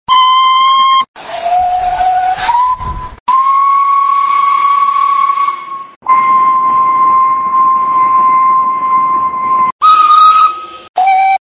Recorded Whistles for Live Steam Locomotives
Each whistle set contains six instances of the selected whistle in a ZIP file.
whistles_welsh_pony.mp3